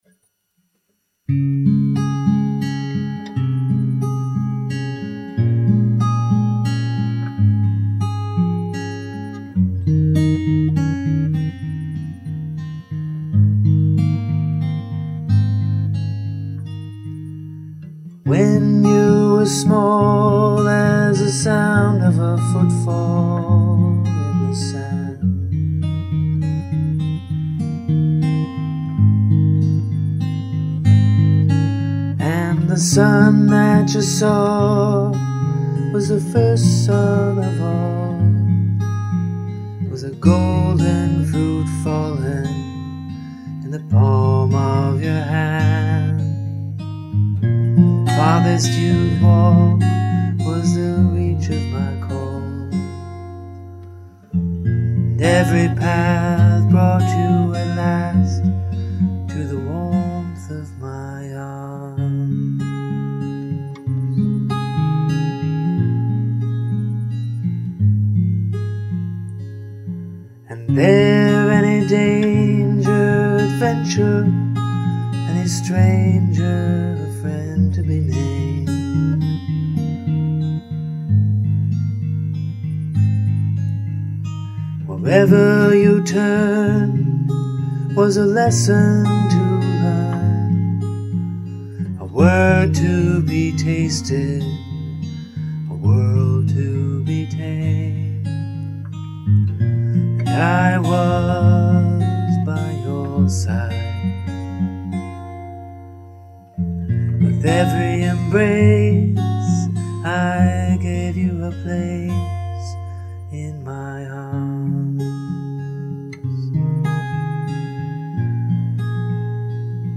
In 2002, I made a scratch track of this song – I suppose that, for public consumption, I really shouldn’t record when I have a cold, or use a laptop microphone to pick up the sound.